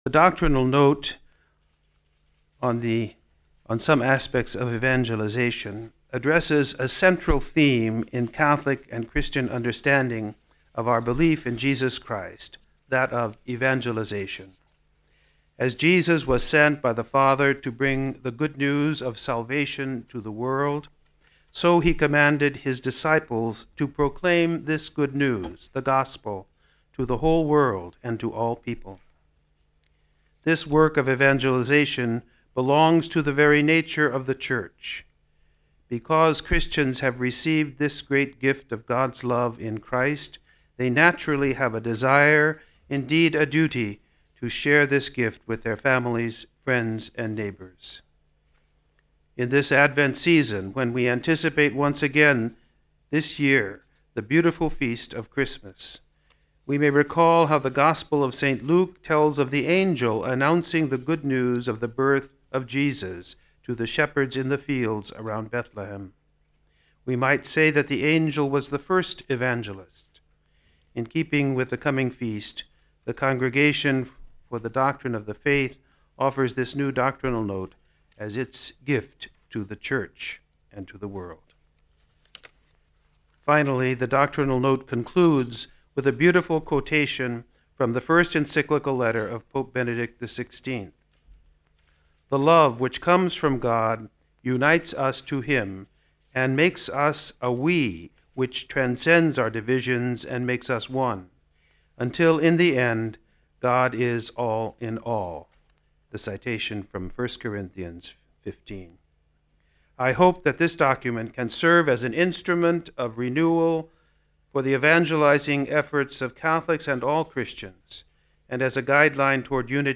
(14 Dec 07 - RV) Prefect of the Congregation for the Doctrine of the Faith (CDF), US Cardinal William Joseph Levada, presented the document, entitled a Doctrinal Note on some Aspects of Evangelization to journalists in the Vatican’s Press Office Friday at Noon.
Hear Cardinal Levada's intervention: RealAudio